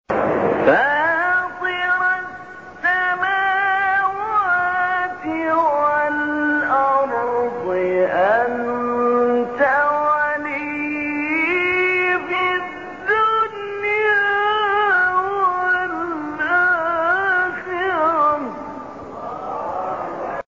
به گزارش خبرگزاری بین المللی قرآن(ایکنا)، شش فراز صوتی با صوت محمود علی البناء، قاری برجسته مصری در کانال تلگرامی قاریان مصری منتشر شده است.
این مقاطع صوتی از تلاوت سوره یوسف بوده است که در مقام‌های بیات، صبا، نهاوند، رست و سه گاه اجرا شده‌اند.
مقام سه‌گاه